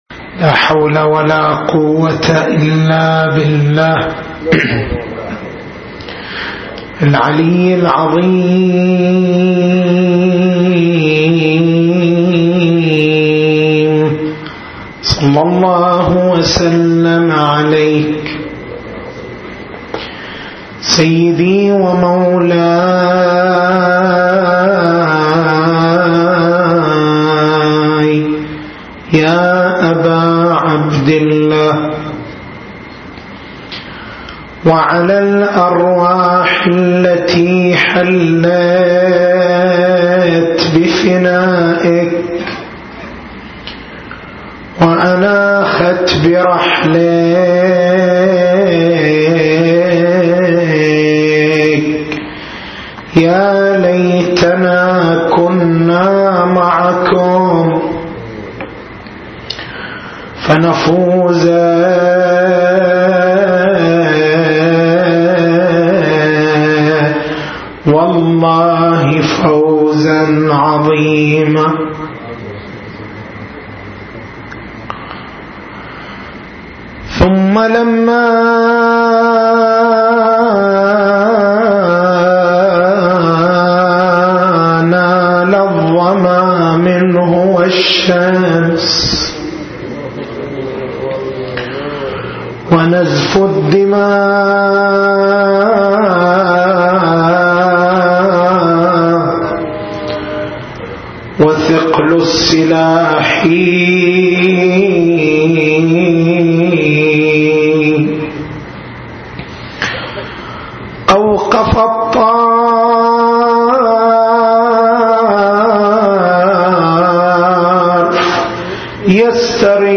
تاريخ المحاضرة: 12/01/1434 نقاط البحث: هل الولاية والبراءة من أصول الدين، أم هما من فروعه؟